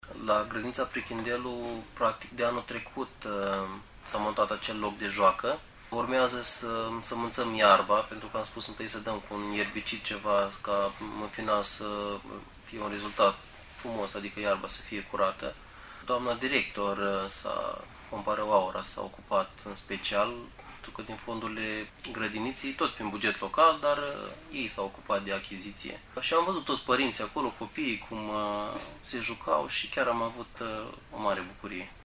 Viceprimarul municipiului Rădăuți, Bogdan Loghin, a spus, la VIVA FM, că mai este nevoie să crească gazonul care va înverzi zona, însă copiii au acces la locul de joacă unde își petrec timpul liber împreună cu părinții.